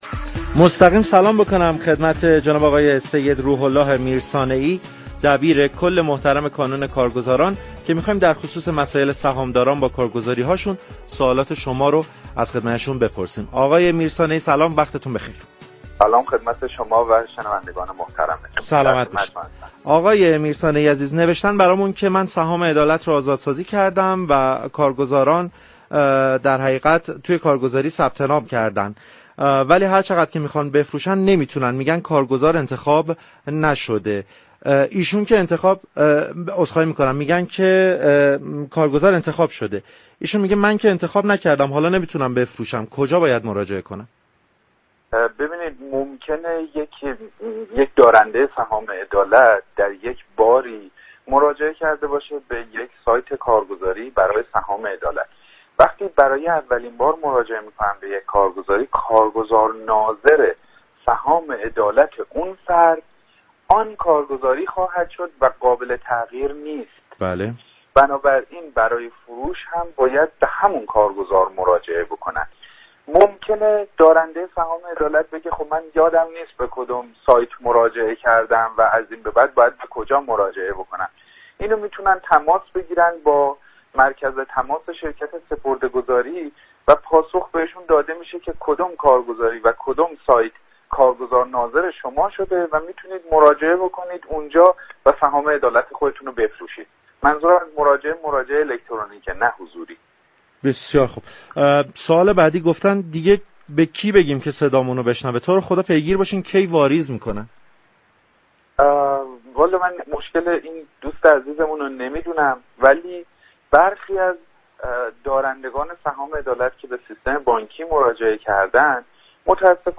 در گفتگو با برنامه بازار تهران